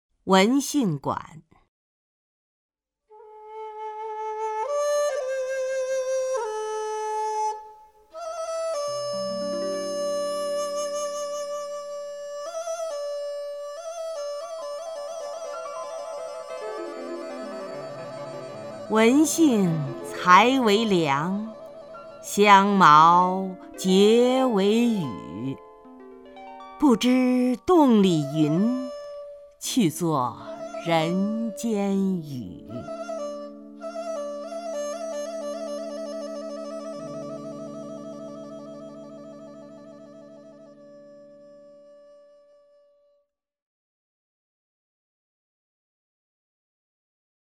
曹雷朗诵：《文杏馆》(（唐）王维) （唐）王维 名家朗诵欣赏曹雷 语文PLUS